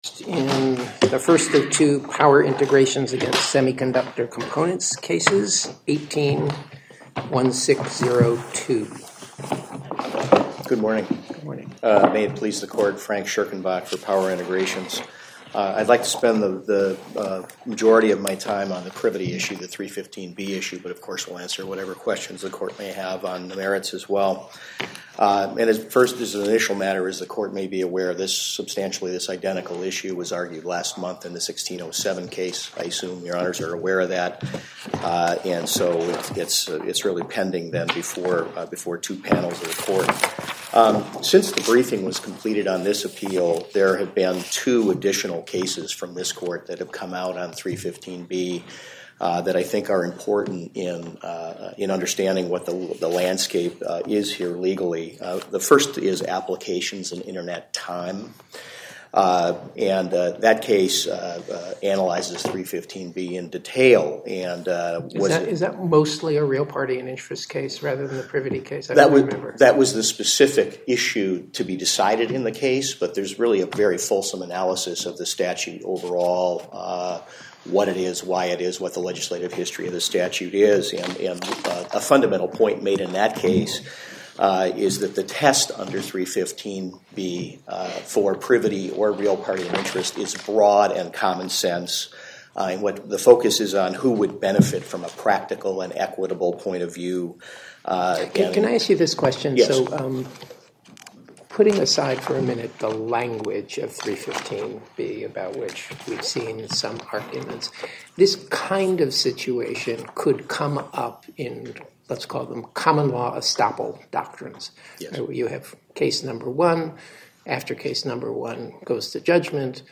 To listen to more oral argument recordings, follow this link: Listen To Oral Arguments.